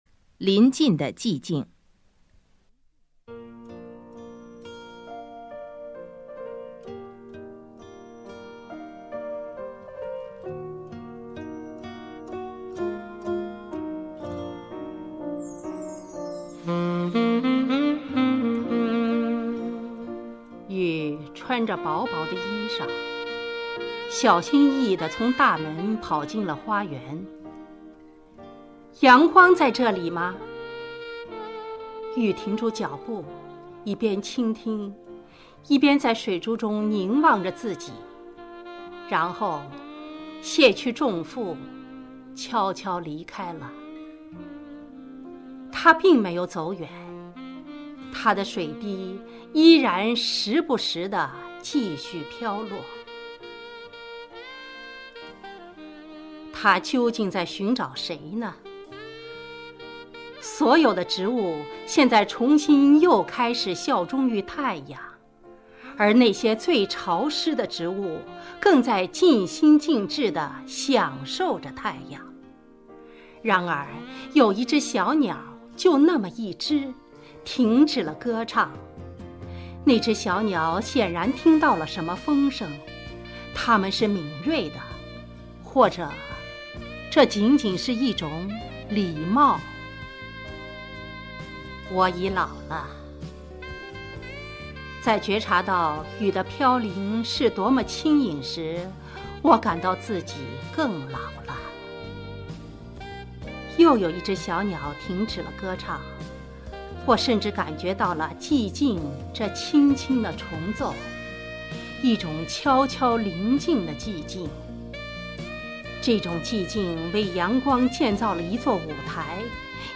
朗诵：《临近的寂静》(佚名)